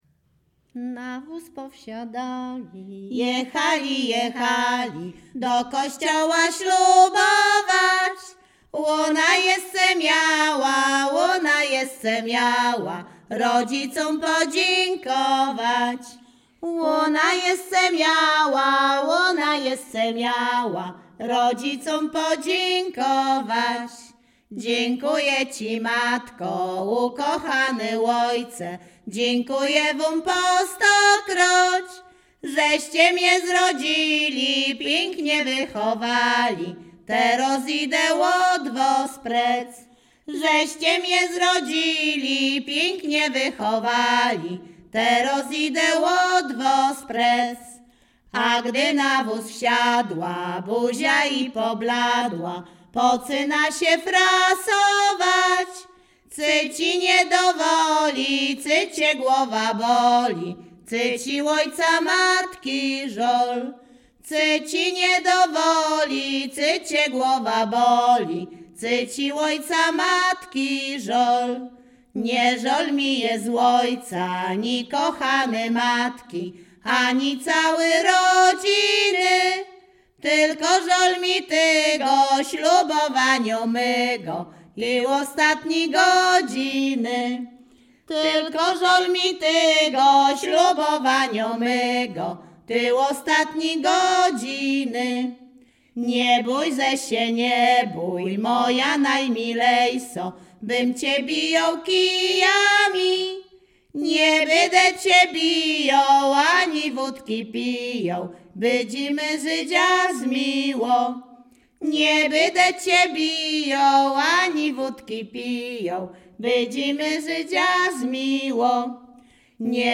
Śpiewaczki z Chojnego
województwo łódzkie, powiat sieradzki, gmina Sieradz, wieś Chojne
Weselna